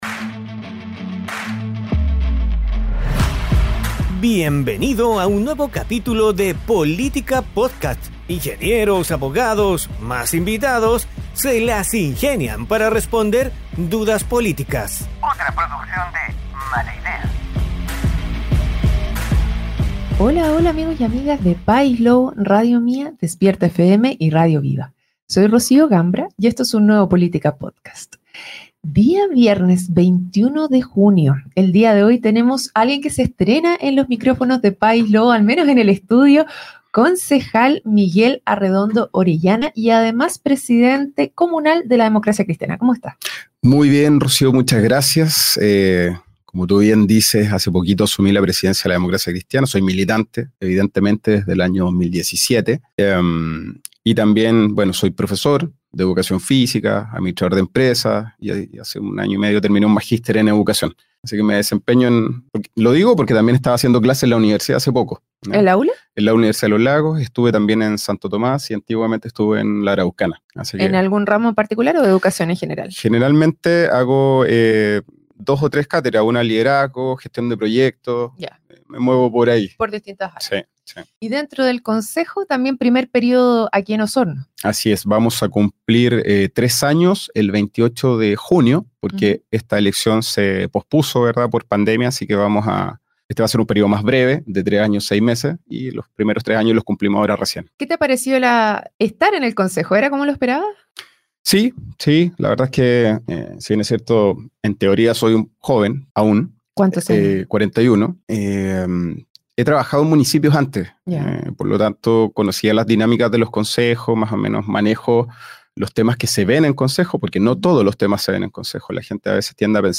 Hoy en nuestro programa, conversamos con el concejal Miguel Arredondo Orellana, presidente comunal de la Democracia Cristiana (DC).